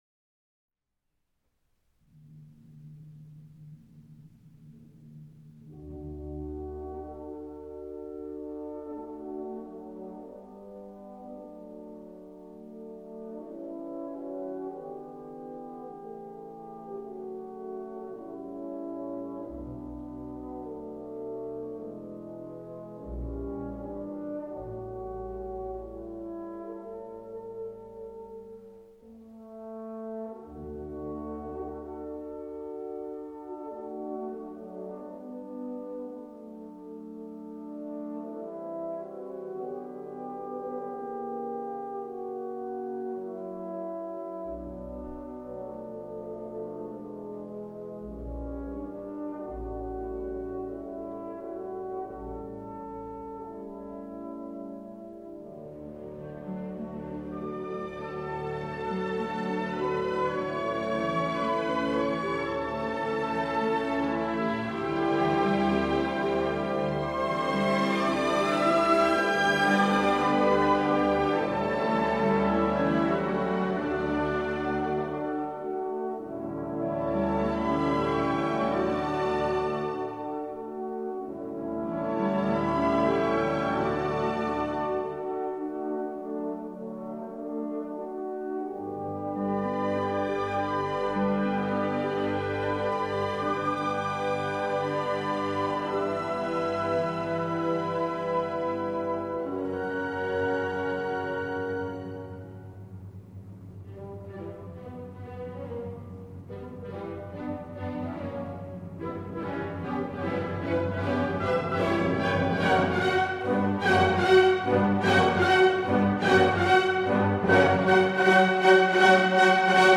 suite de ballet